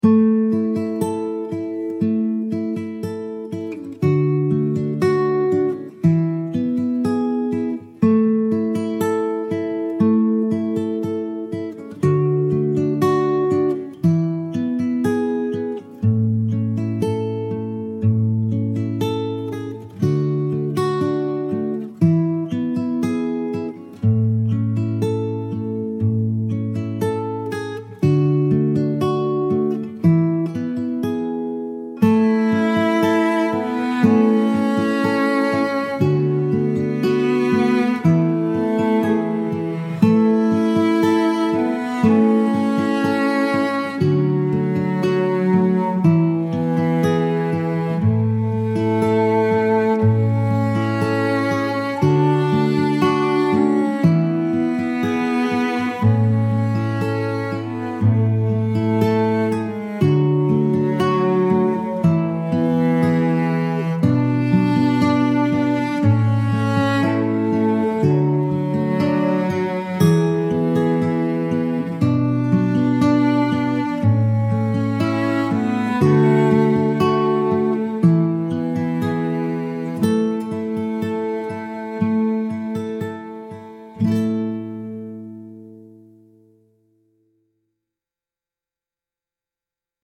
intimate homey music with fingerpicked guitar and warm cello